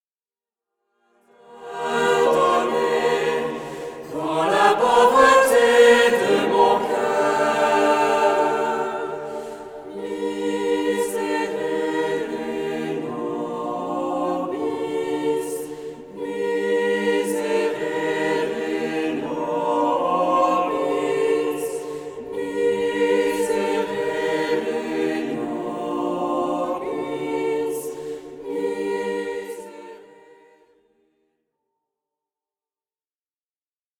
choeur